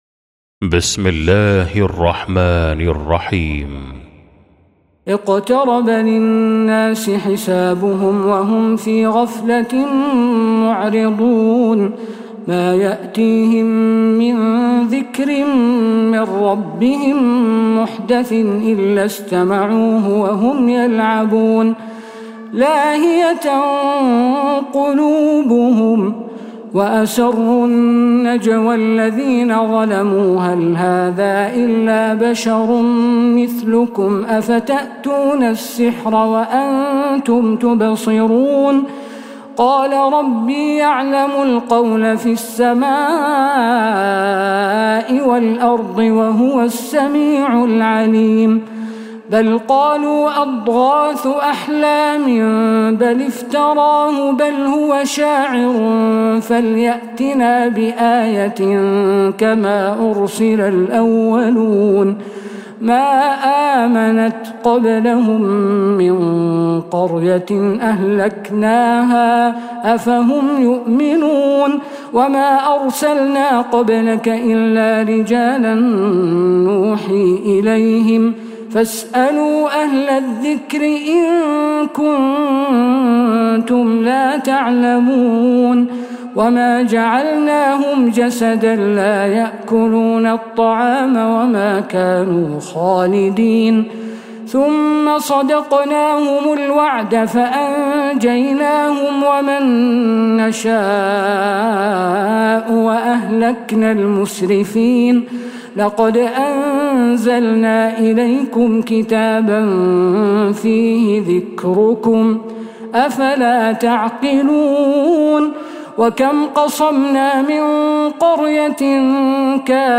سورة الأنبياء | Surah Al-Anbiya > مصحف تراويح الحرم النبوي عام 1446هـ > المصحف - تلاوات الحرمين